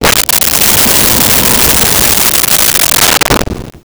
Wind Howl 03
Wind Howl 03.wav